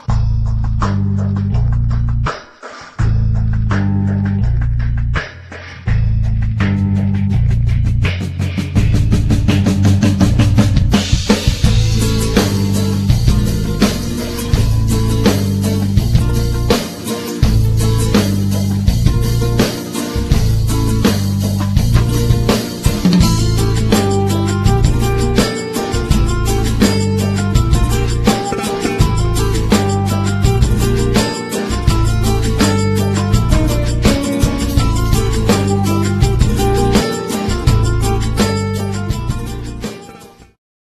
gitara akustyczna / acoustic guitar